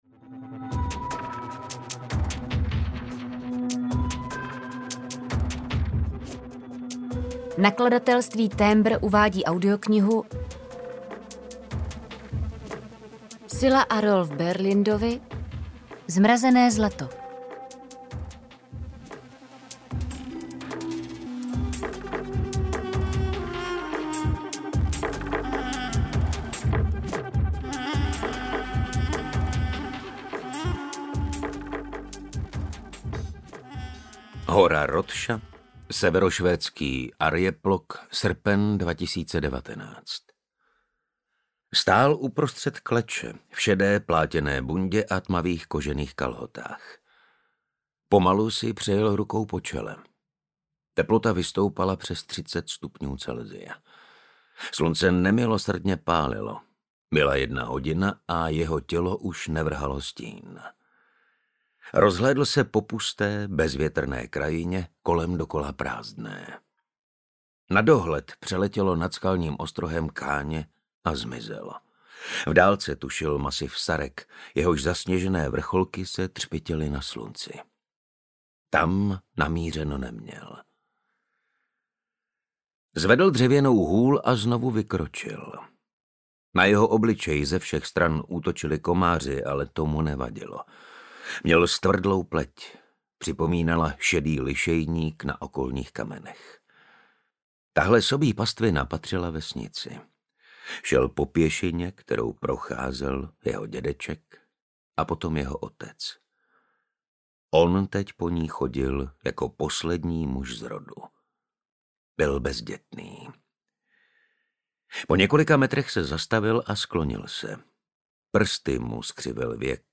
Zmrazené zlato audiokniha
Ukázka z knihy
zmrazene-zlato-audiokniha